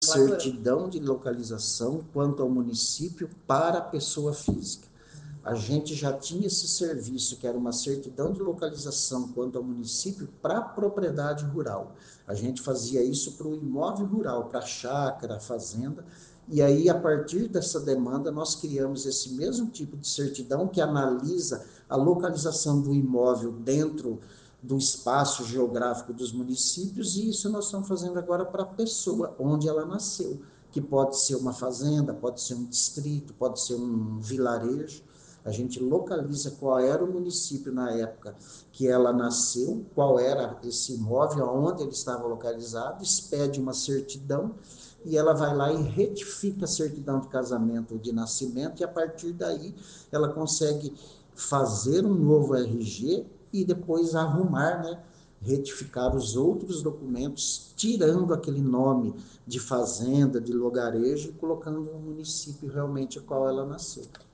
Em entrevista à FM Educativa MS